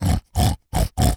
pig_sniff_deep_02.wav